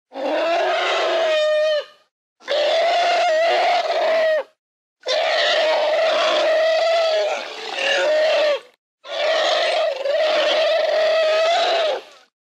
Крики вероятного мамонтёнка